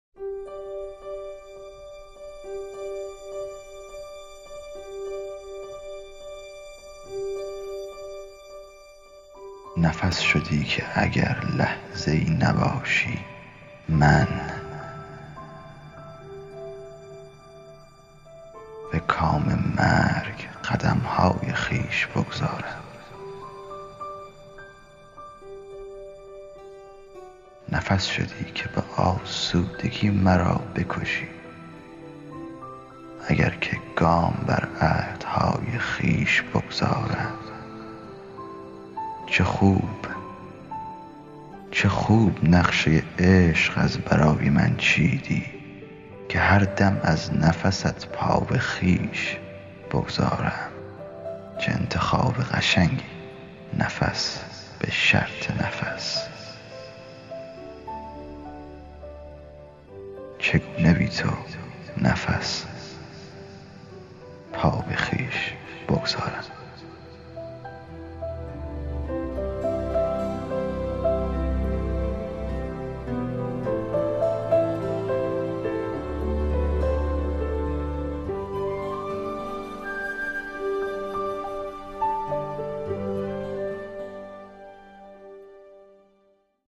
دکلمه
میکس و مسترینگ